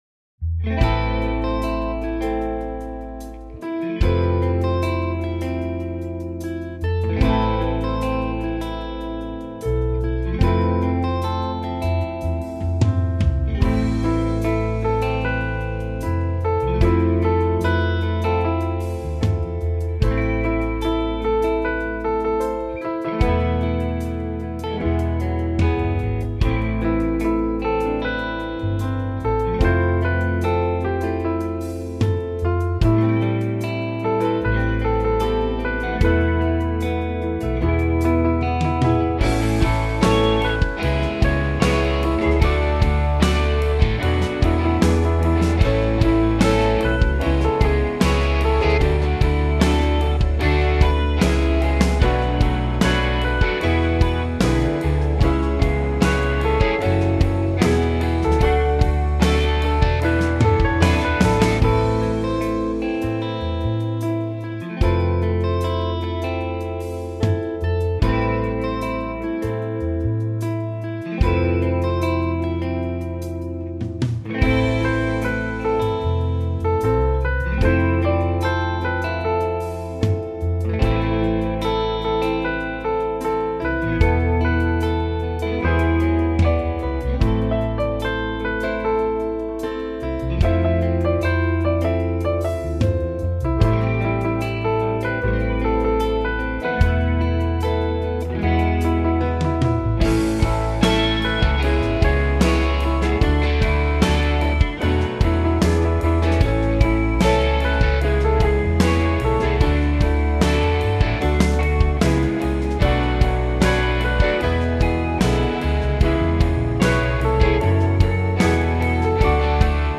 It has a touch of the show tune to it.